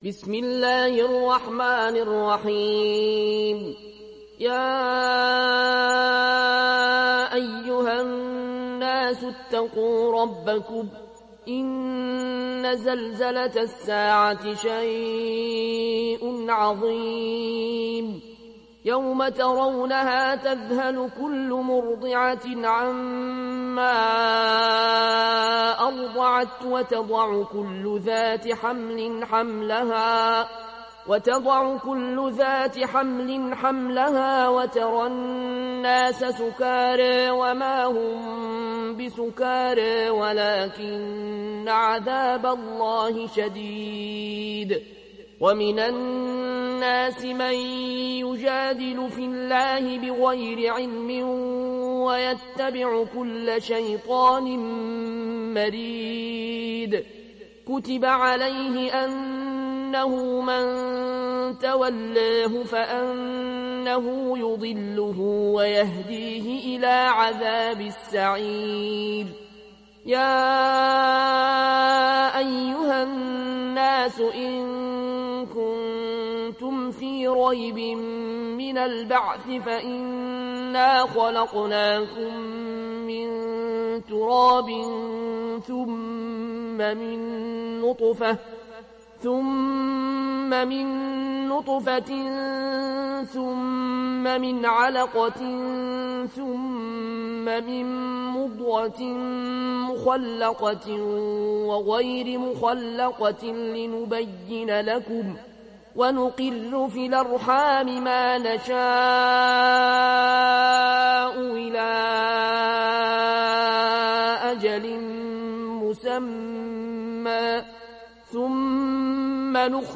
Riwayat Warsh an Nafi